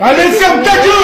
Category: Meme Soundboard